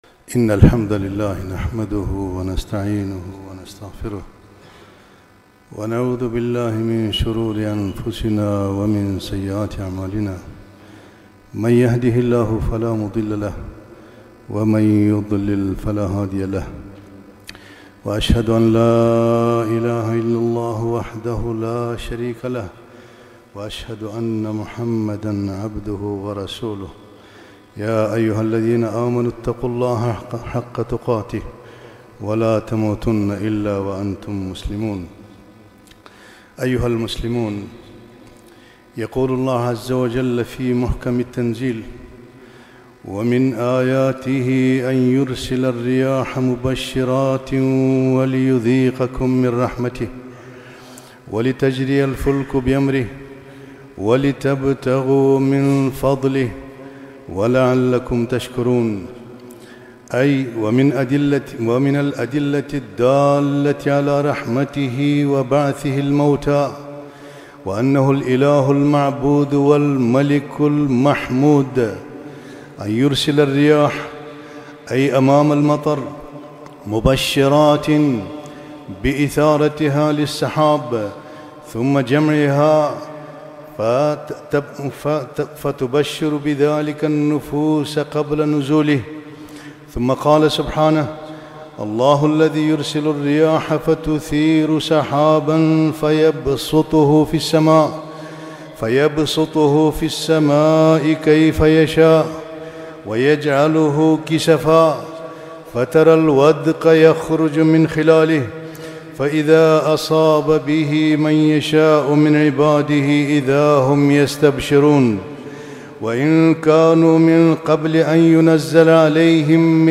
خطبة - الرياح آية من آيات الله